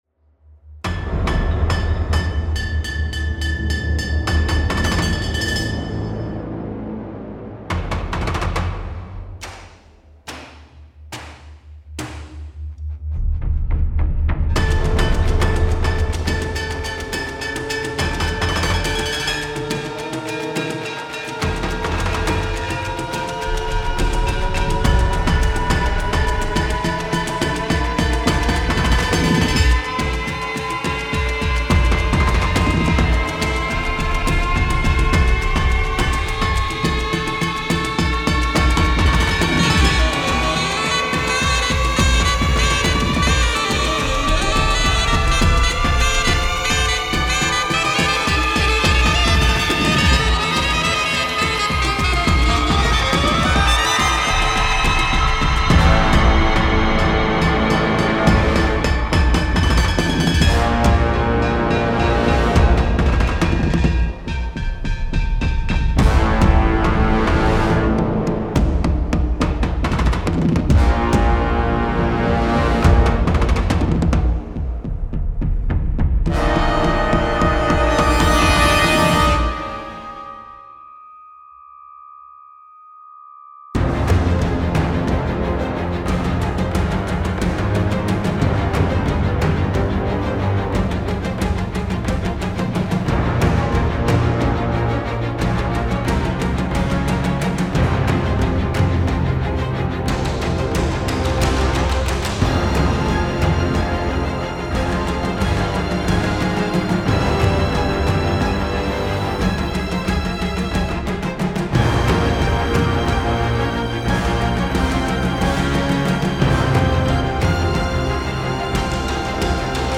Approche éclatée de western urbain